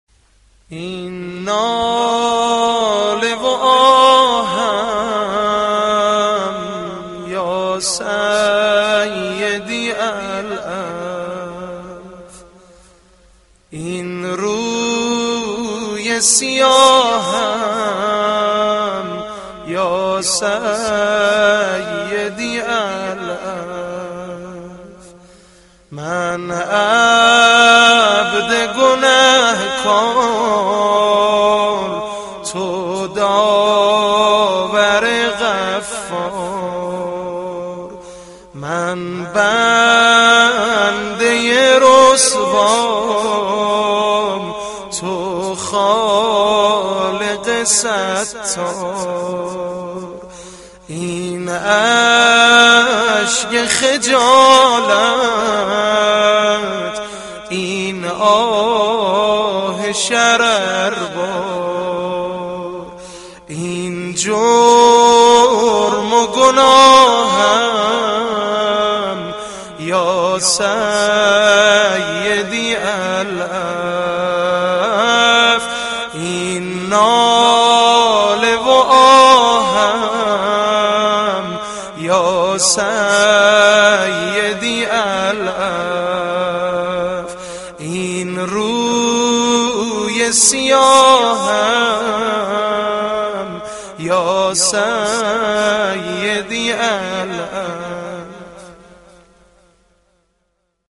┄━═✿♡﷽♡✿═━┄ ➖➖➖➖➖ این ناله و آهم یا سیدی العفو ➖➖➖➖➖ مناجات – رمضانیه ..